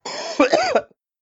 mixkit-man-coughing-loud-2222.ogg